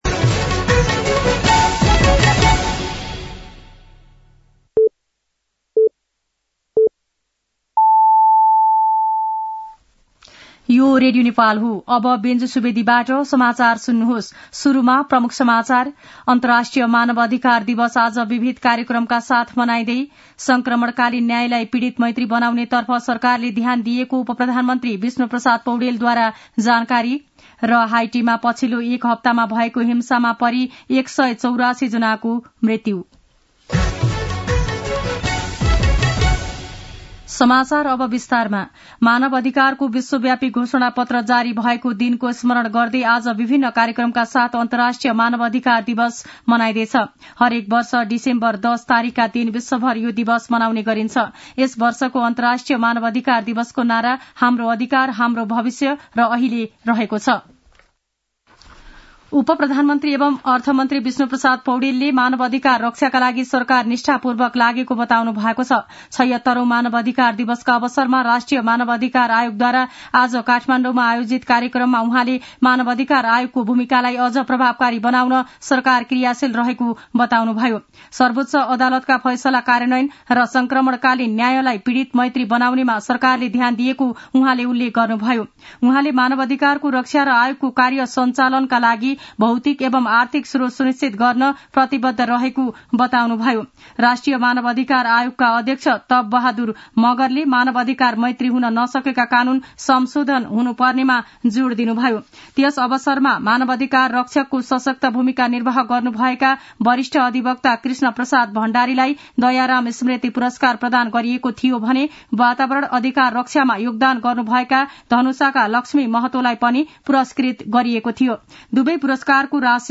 दिउँसो ३ बजेको नेपाली समाचार : २६ मंसिर , २०८१
3-pm-Nepali-news-.mp3